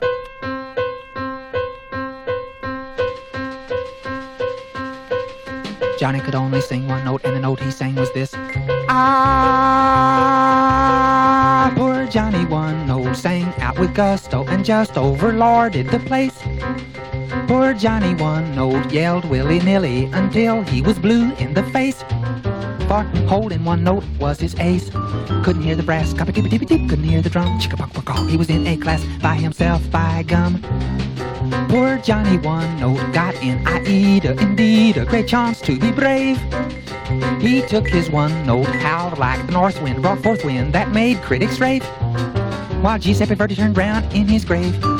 Jazz, Pop, Vocal　USA　12inchレコード　33rpm　Stereo